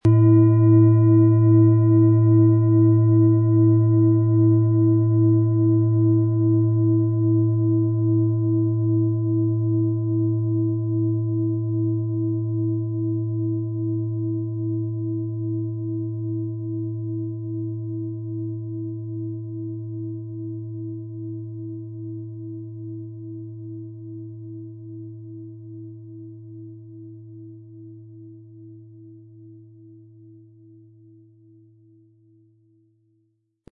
• Mittlerer Ton: Hopi-Herzton
Im Sound-Player - Jetzt reinhören können Sie den Original-Ton genau dieser Schale anhören.
Aber dann würde der ungewöhnliche Ton und das einzigartige, bewegende Schwingen der traditionellen Herstellung fehlen.
PlanetentöneThetawelle & Hopi-Herzton
MaterialBronze